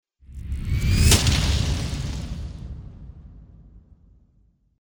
FX-190-RISING-IMPACT
FX-190-RISING-IMPACT.mp3